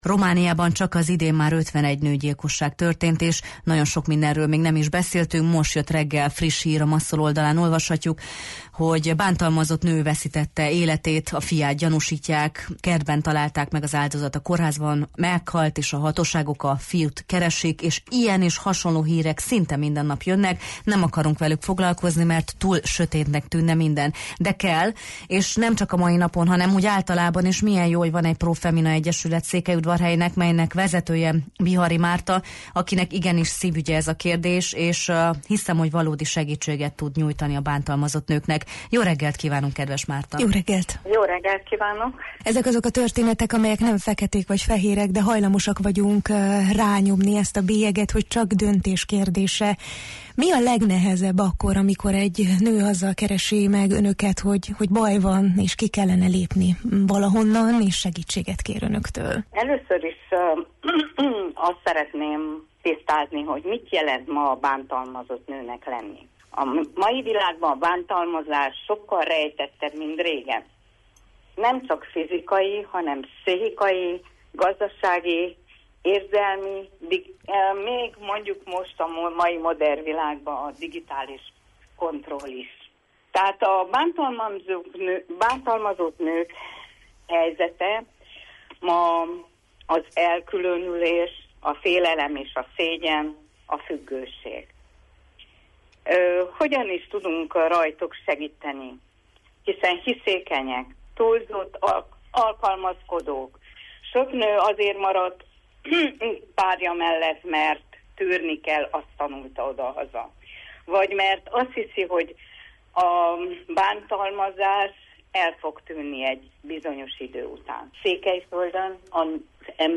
Ez a beszélgetés felhívás is: figyelni, érteni és cselekedni kell.